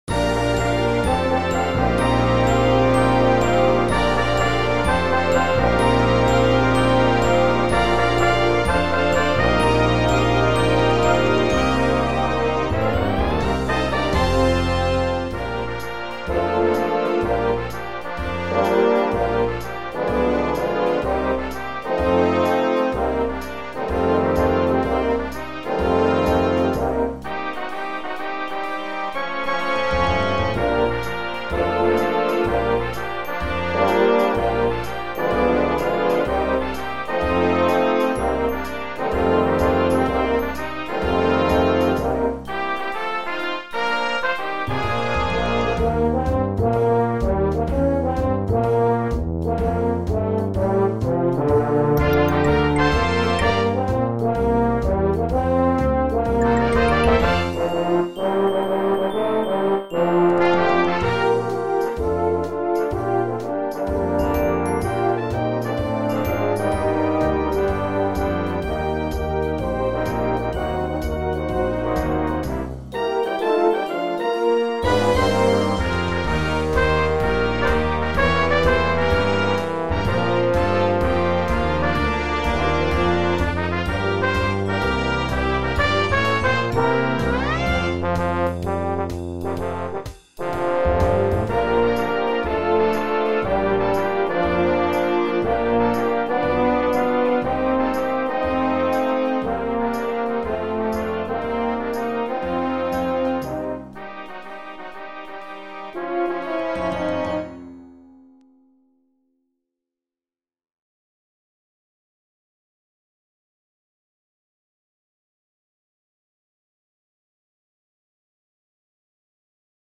(computer generated sound sample)
07B1 Brass Band $50.00